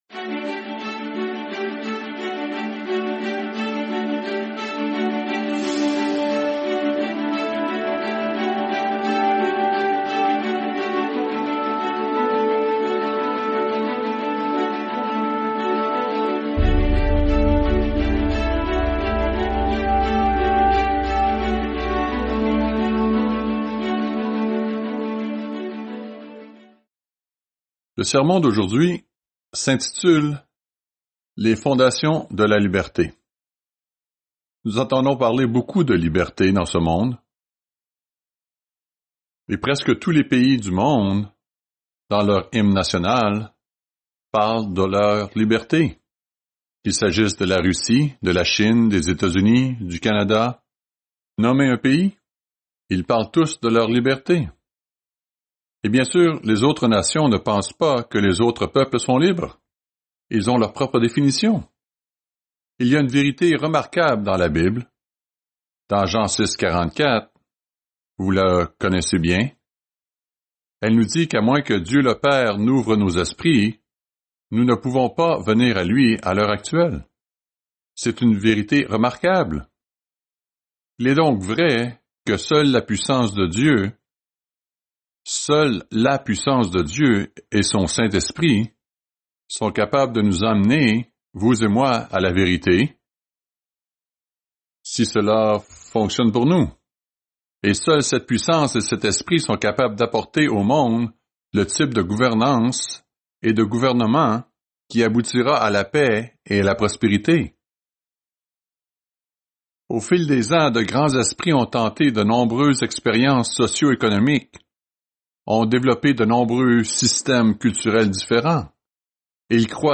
Fête des Tabernacles – 5e jour